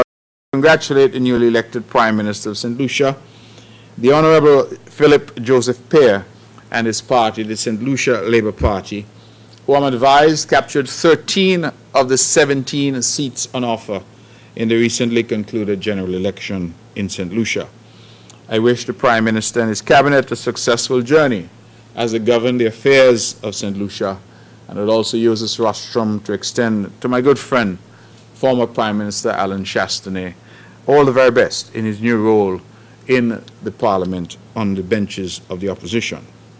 Premier of Nevis, sent congratulatory remarks to newly elected Prime Minister of St. Lucia – Phillip J. Pierre.
Premier of Nevis the Hon. Mark Brantley.